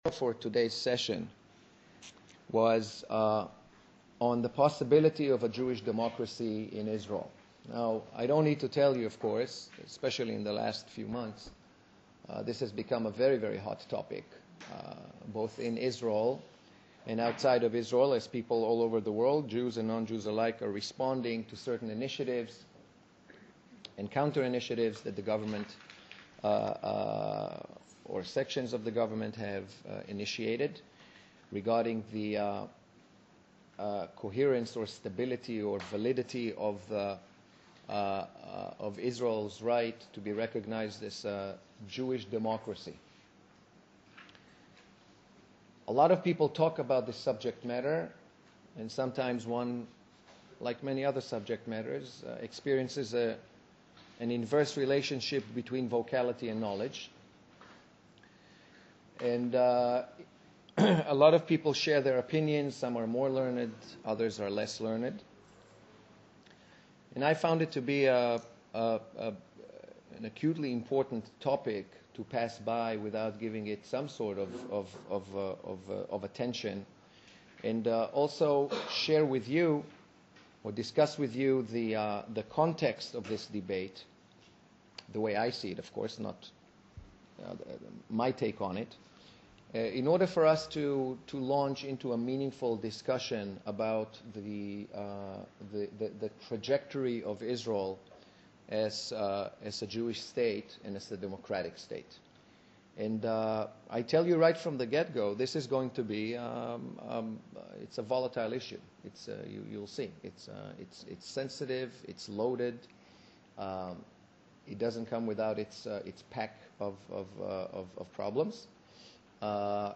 Final Lecture